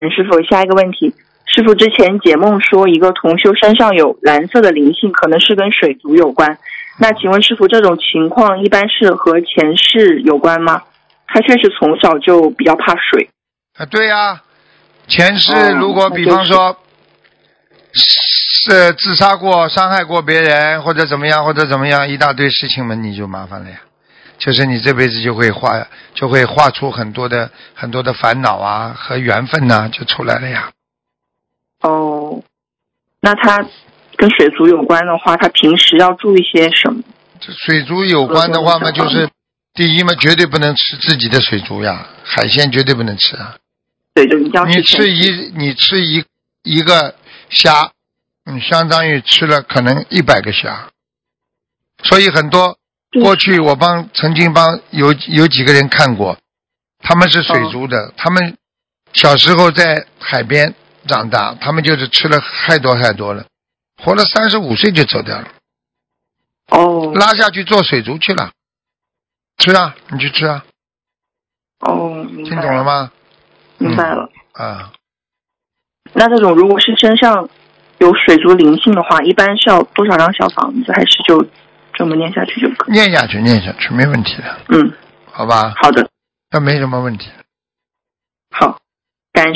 目录：☞ 2019年12月_剪辑电台节目录音_集锦